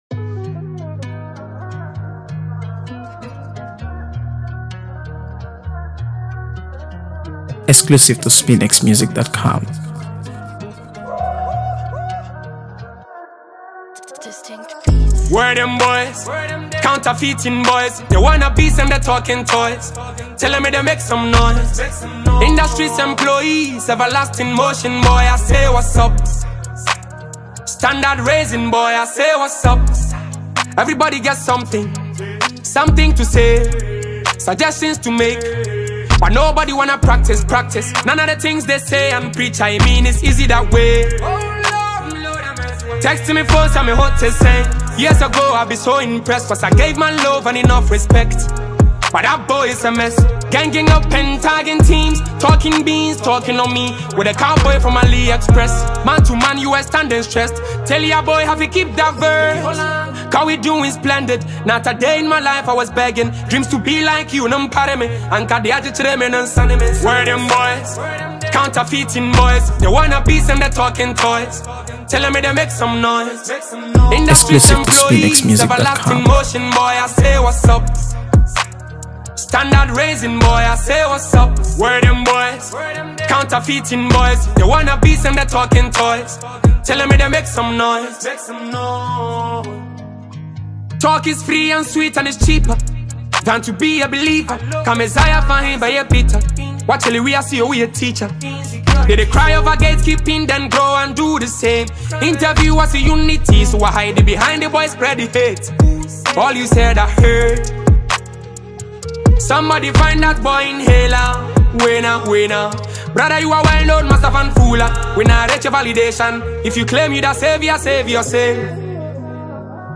AfroBeats | AfroBeats songs
a bold, energetic track